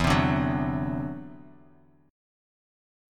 E7sus2#5 Chord
Listen to E7sus2#5 strummed